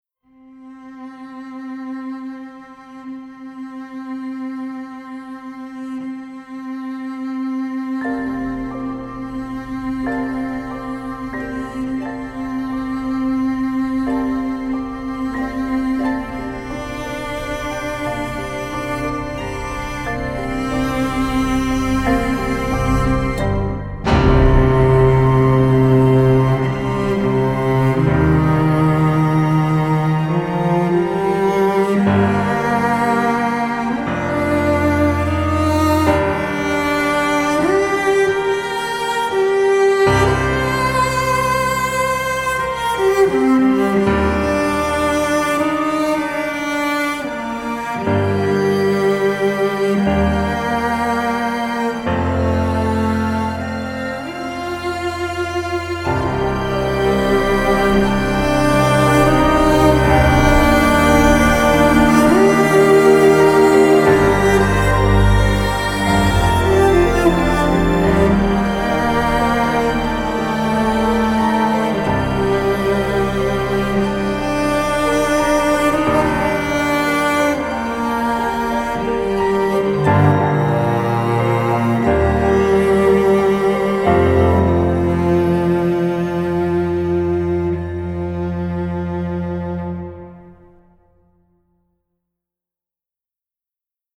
SUPERSTAR CELLO